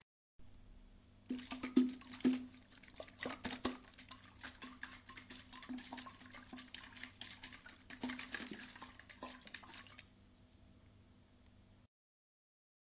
摇晃的水瓶
描述：摇瓶水。
Tag: 摇动 瓶子 飞溅 塑料 泡沫 OWI